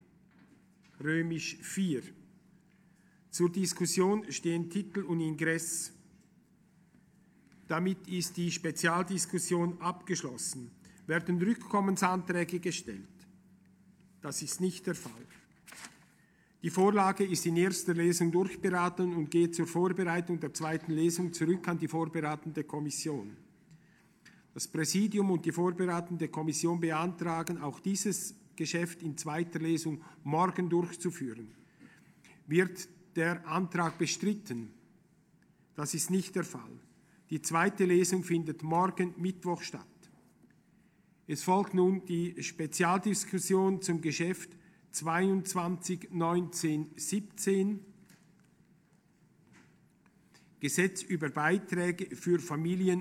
Session des Kantonsrates vom 18. bis 20. Mai 2020, Aufräumsession